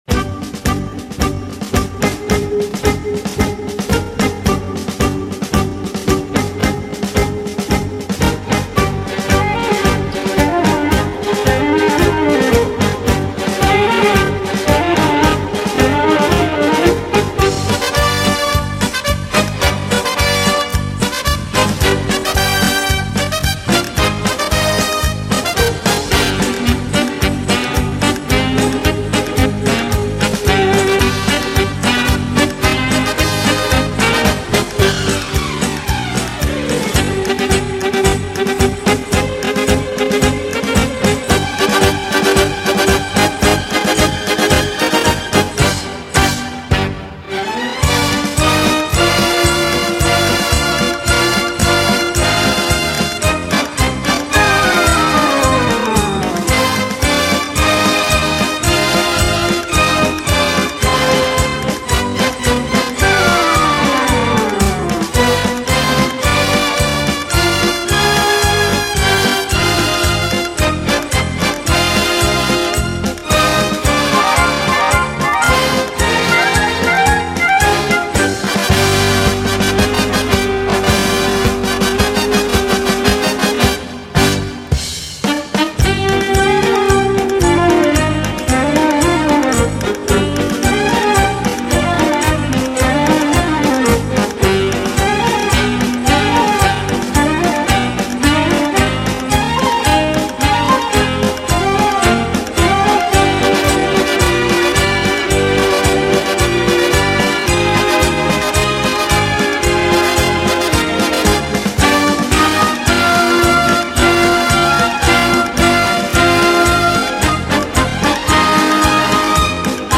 Download .mp3 "Malaguena" Paso Doble 2 – Solos will be performed on the day/one of the days that has that style of Dance.